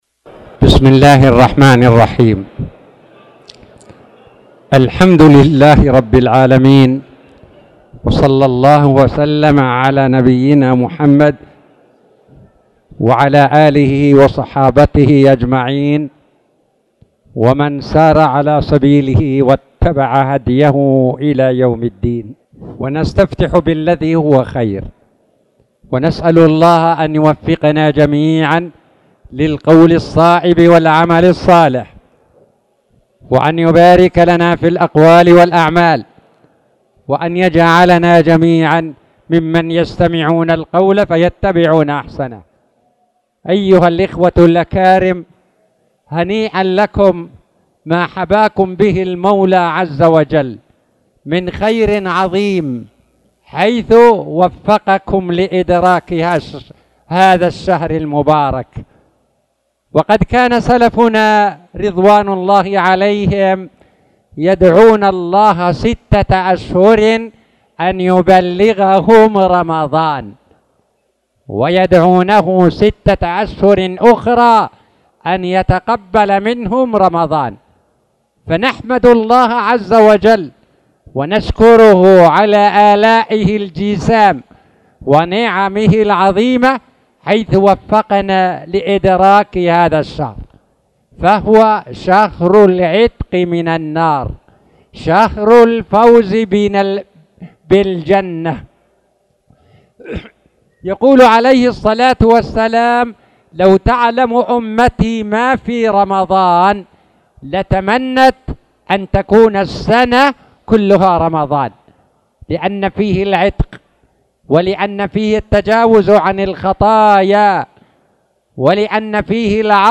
تاريخ النشر ١ رمضان ١٤٣٧ هـ المكان: المسجد الحرام الشيخ